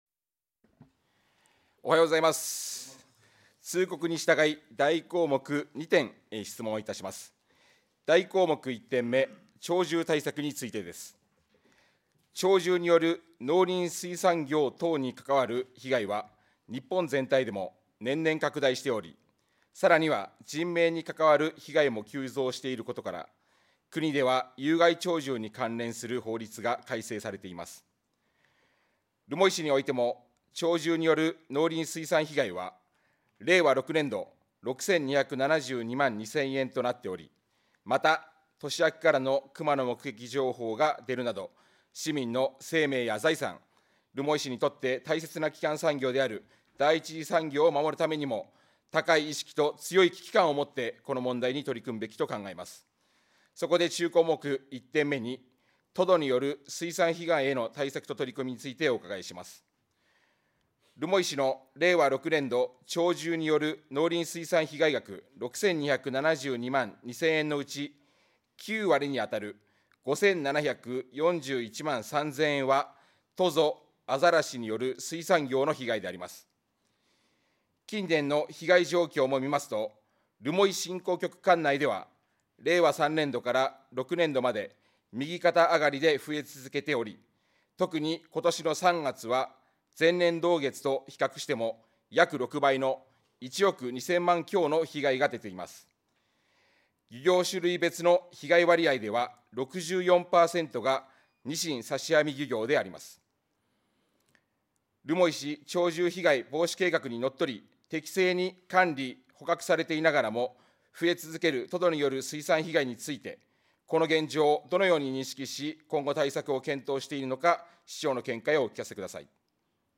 議会録音音声